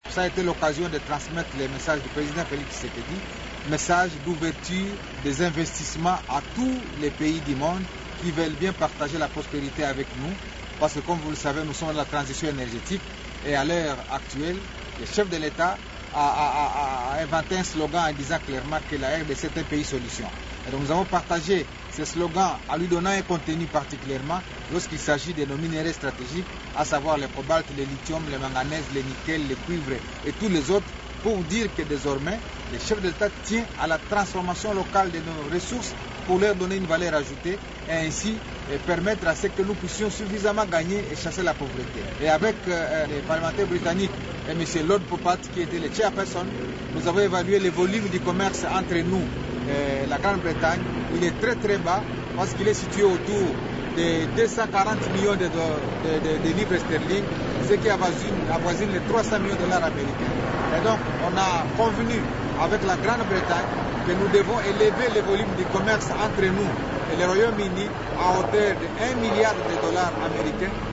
C’était en marge du forum économique Royaume-Uni Grande Bretagne qui s’est tenu cette semaine dans la capitale britannique.